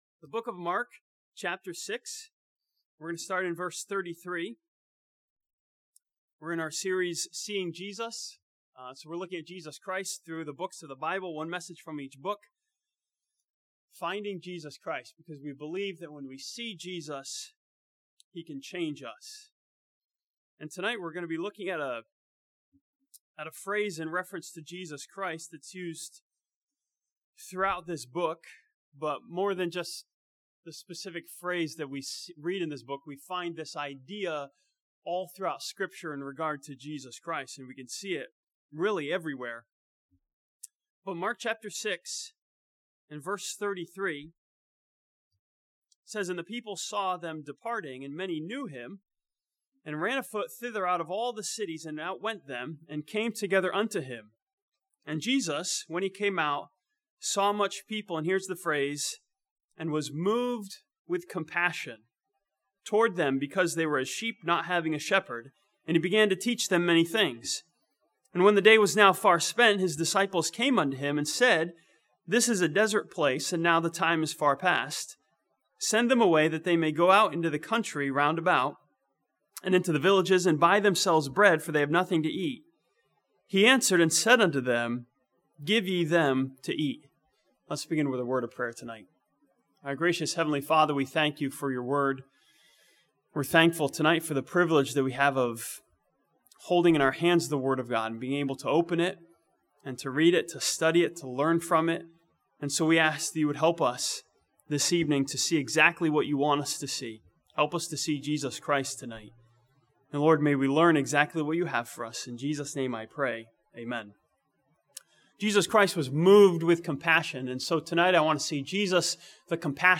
This sermon from Mark chapter 6 sees Jesus the compassionate as He is moved with compassion toward the multitude.